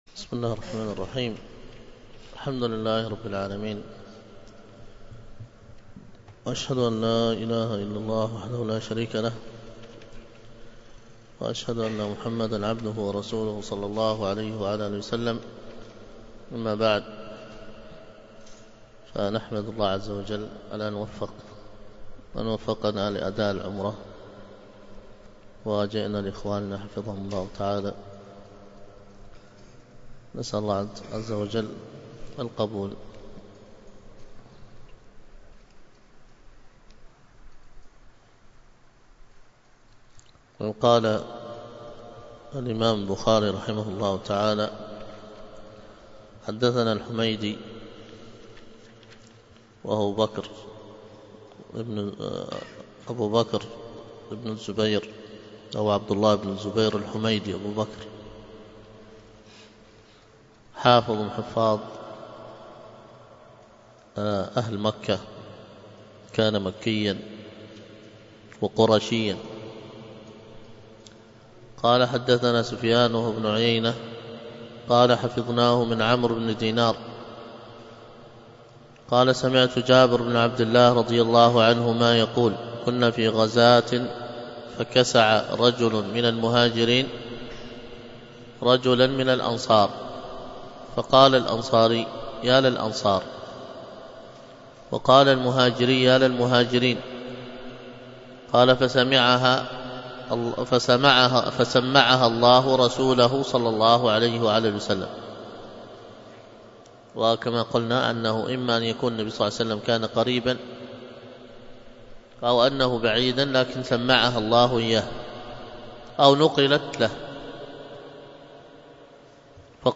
الدرس في كتاب الحج 1، ألقاها